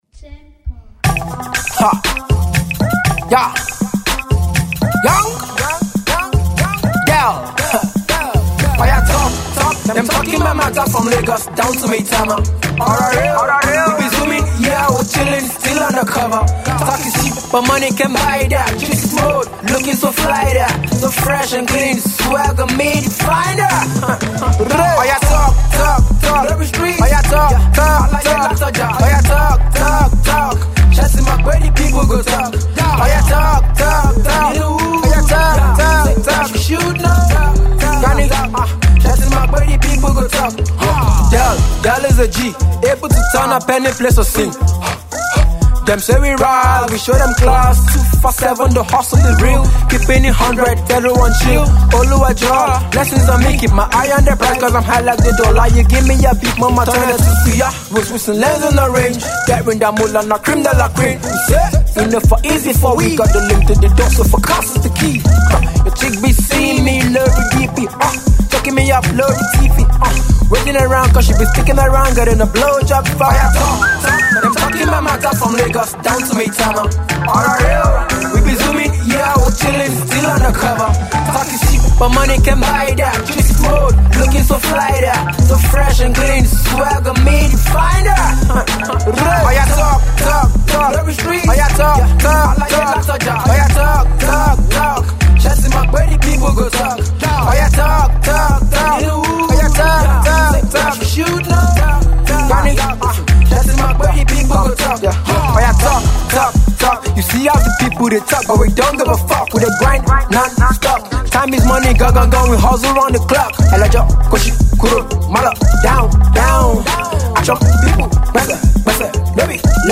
an afro-trap jam